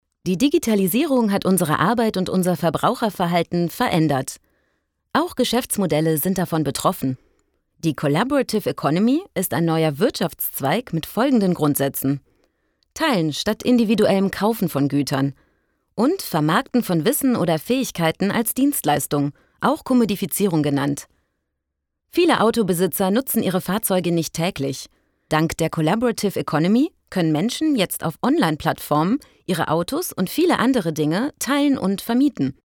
klar, angenehm und freundlich, mit einer weiten emotionalen Bandbreite
Sprechprobe: eLearning (Muttersprache):
clear, pleasant, friendly and reassuring with a good emotional range